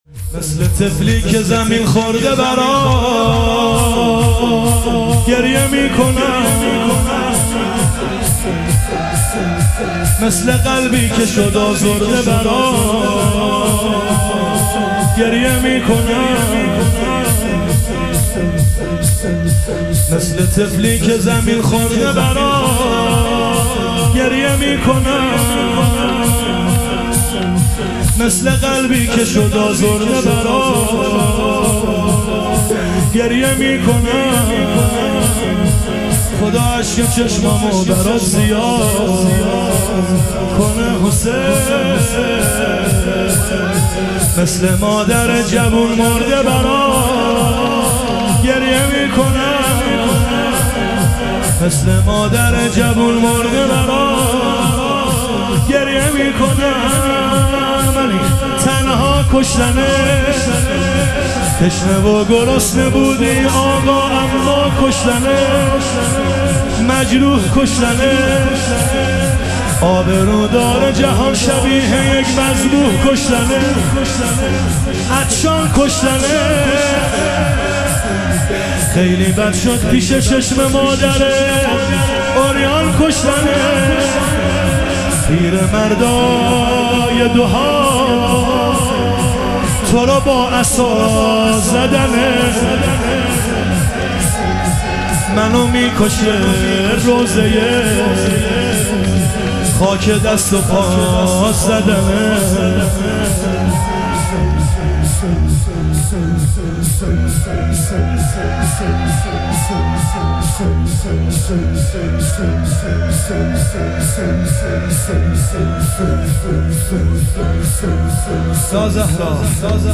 لطمه زنی
شب شهادت حضرت خدیجه علیها سلام